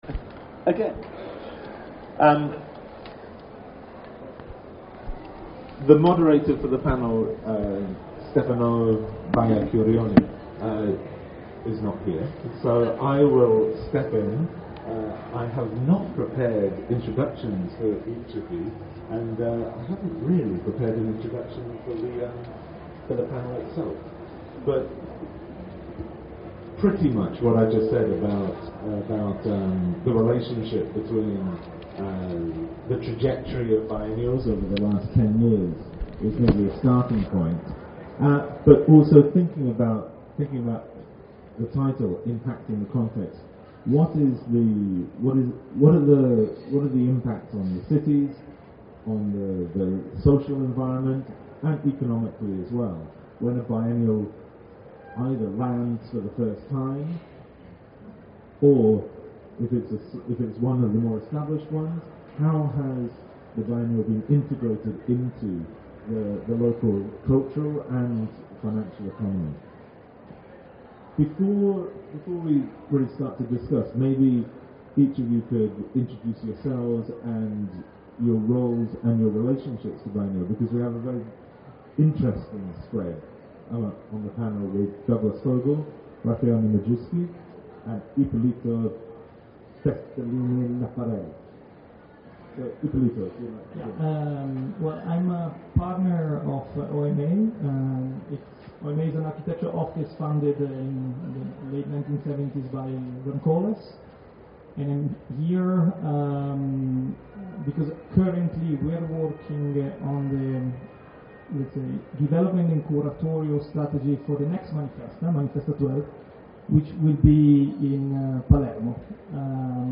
Ieri, venerdì 31 marzo, presso lo spazio dedicato alla sezione miartalks, si è concluso il primo incontro incentrato sull’impatto che eventi come biennali e manifestazioni itineranti (ad esempio MANIFESTA) possono avere sulla rivalutazione delle città da un punto di vista turistico e urbanistico.